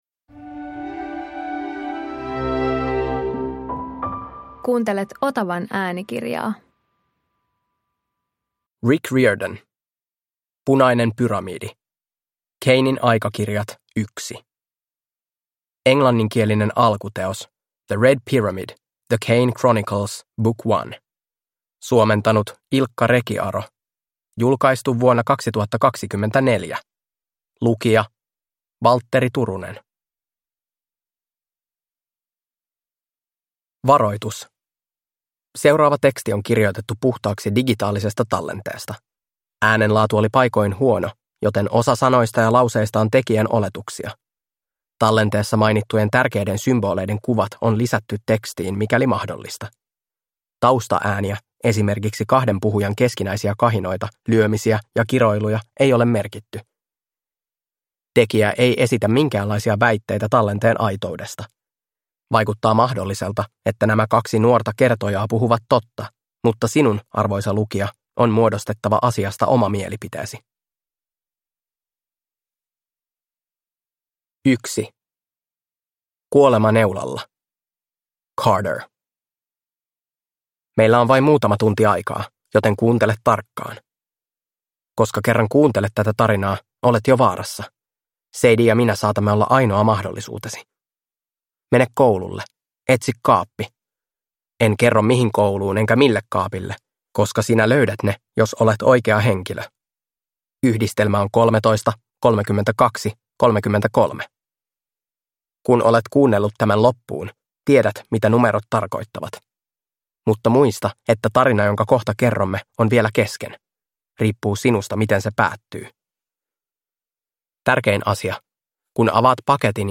Punainen pyramidi – Ljudbok